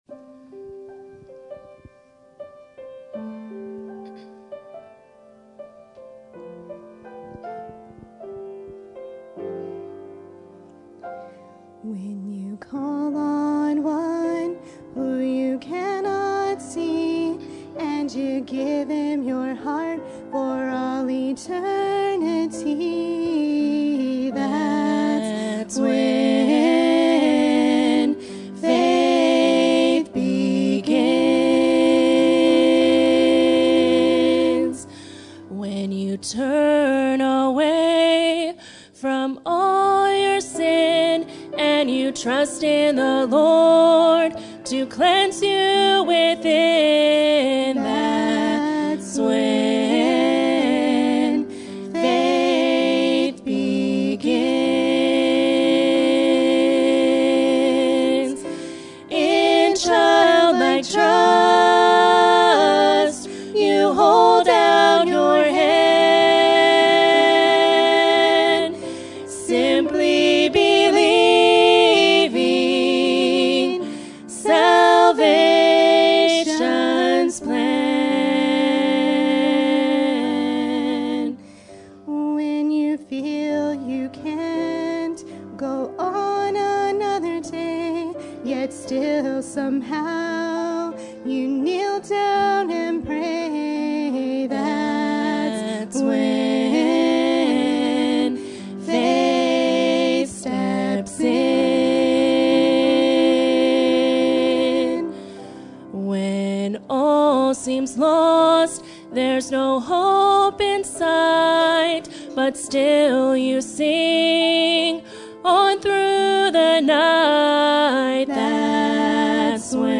Sermon Topic: General Sermon Type: Service Sermon Audio: Sermon download: Download (31.75 MB) Sermon Tags: James Common Sin Selfish